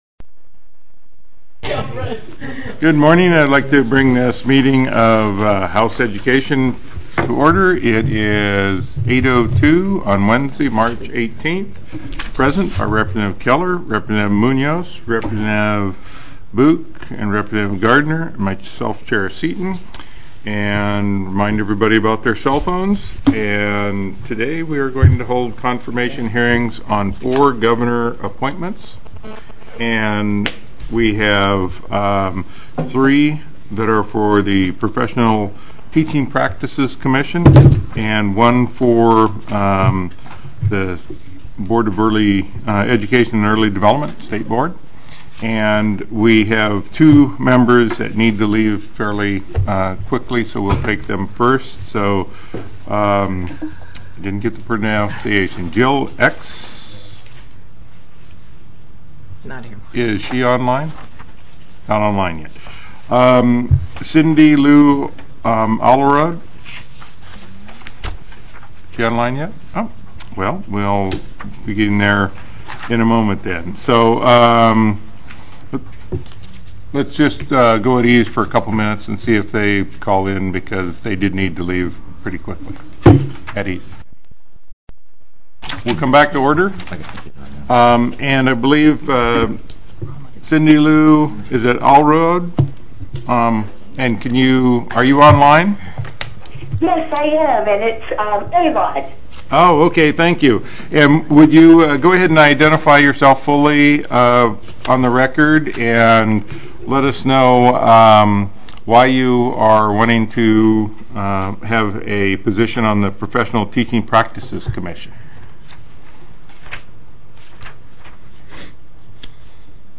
Confirmation Hearings: TELECONFERENCED State Board of Education, Professional Teaching Practices Commission